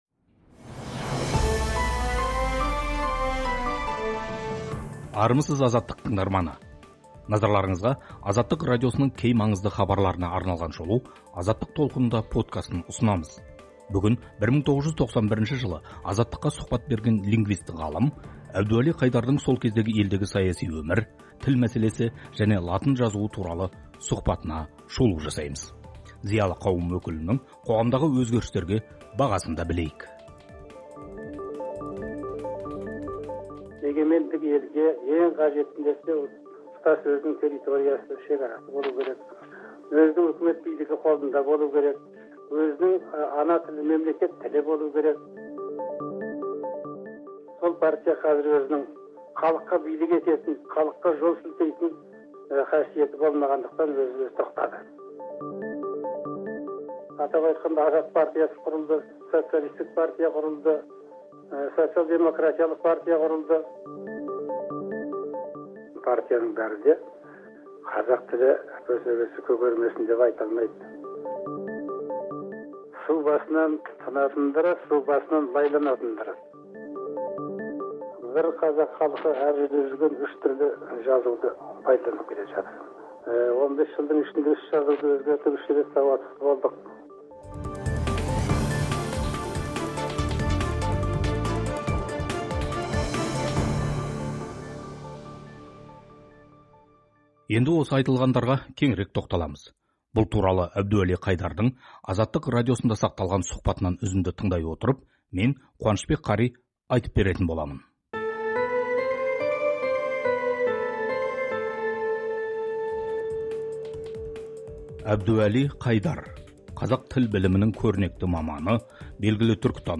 1991 жылы Азаттық радиосына берген сұхбатында этнолингвист ғалым Әбдуәли Қайдар елдегі саяси өмір, көппартиялық қоғамға қадам, тіл мәселесі және латын жазуы туралы ойын бөліскен. Зиялы қауым өкілінің қоғамдағы өзгерістерге берген бағасы – Азаттық радиосының эфирінде.